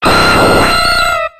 179c8d078b78b721b69ea29ce49d02e5fb272a71 infinitefusion-e18 / Audio / SE / Cries / AERODACTYL.ogg infinitefusion d3662c3f10 update to latest 6.0 release 2023-11-12 21:45:07 -05:00 15 KiB Raw History
AERODACTYL.ogg